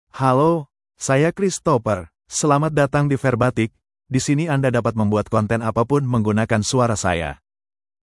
ChristopherMale Indonesian AI voice
Voice sample
Listen to Christopher's male Indonesian voice.
Male
Christopher delivers clear pronunciation with authentic Indonesia Indonesian intonation, making your content sound professionally produced.